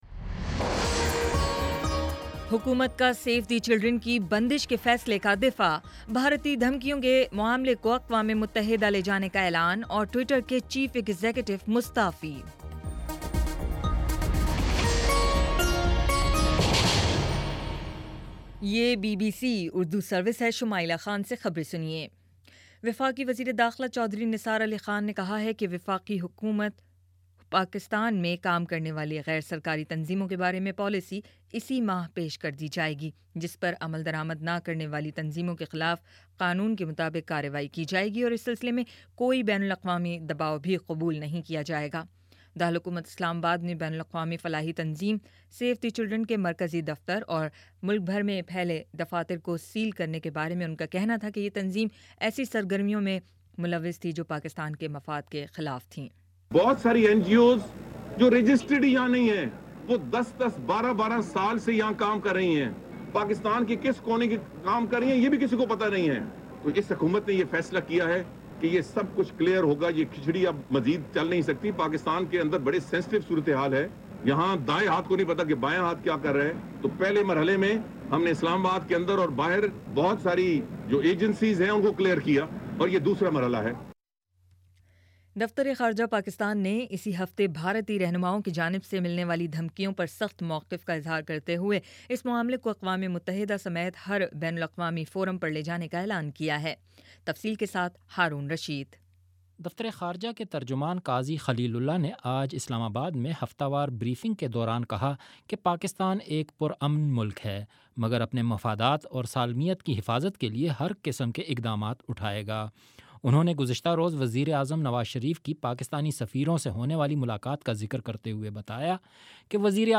جون 12: شام پانچ بجے کا نیوز بُلیٹن